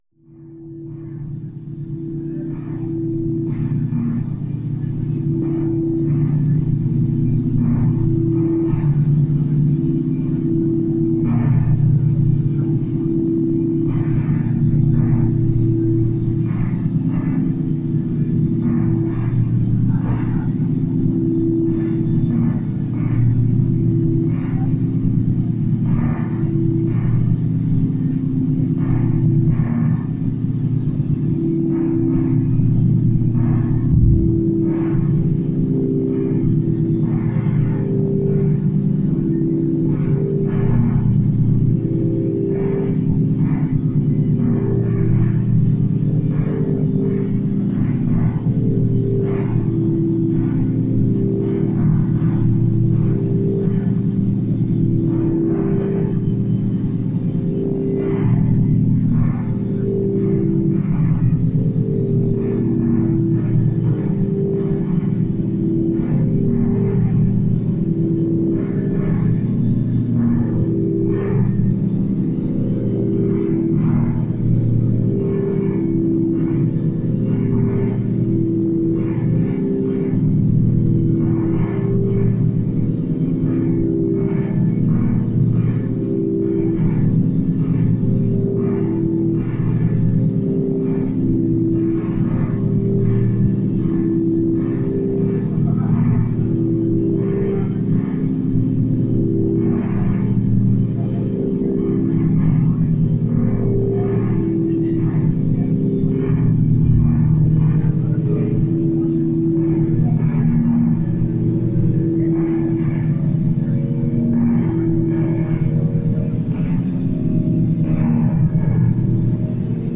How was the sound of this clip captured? live in florida - 2016